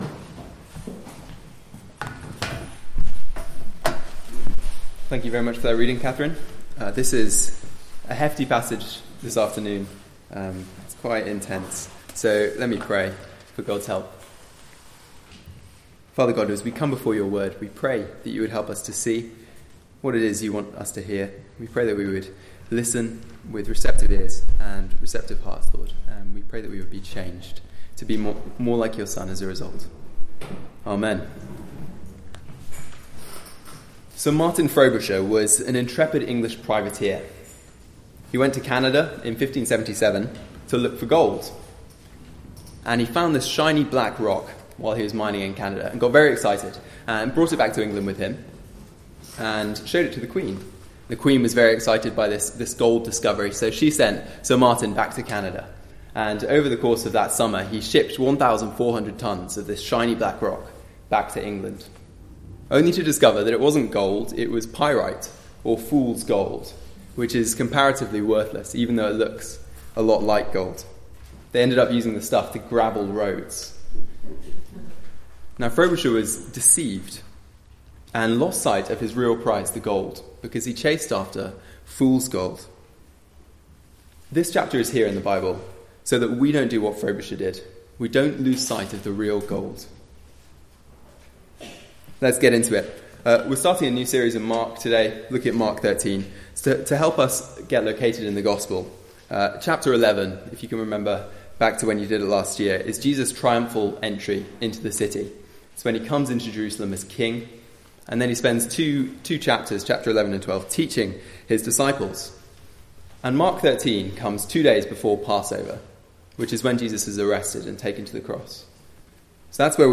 Mark Passage: Mark 13: 1-37 Service Type: Weekly Service at 4pm Bible Text